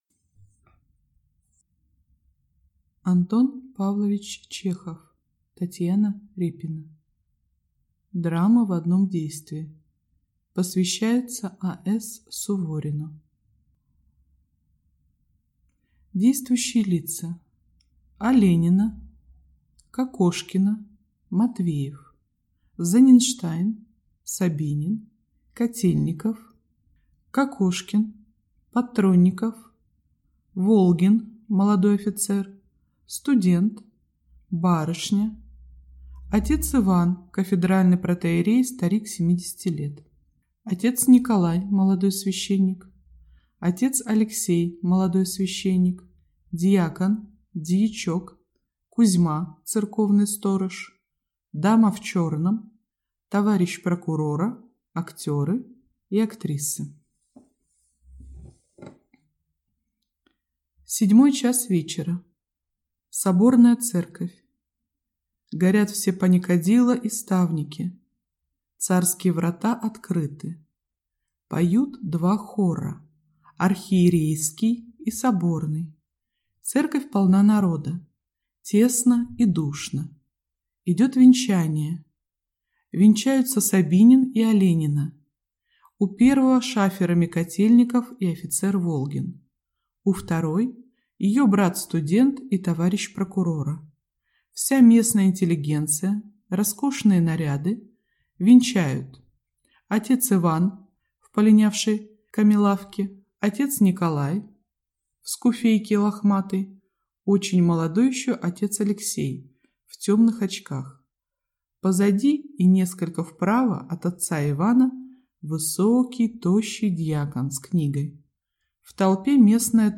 Аудиокнига Татьяна Репина | Библиотека аудиокниг
Прослушать и бесплатно скачать фрагмент аудиокниги